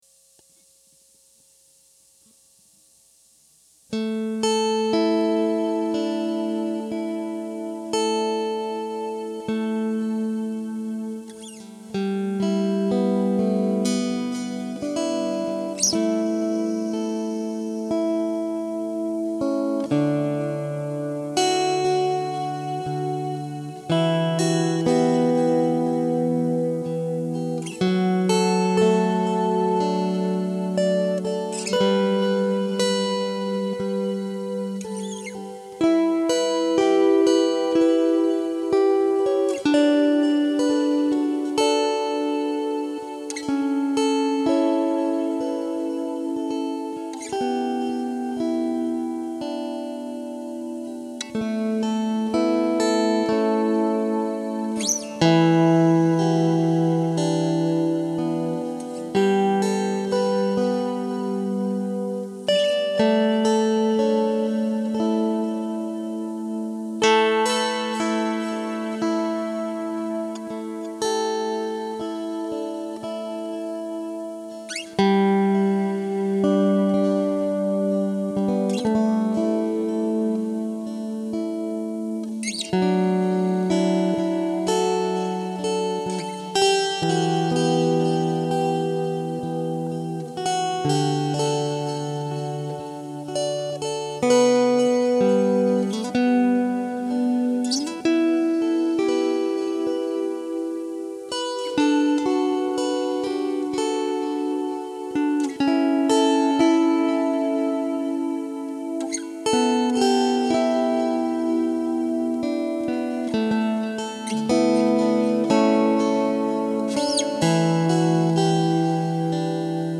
- The Relaxing Sounds of the Dulcimer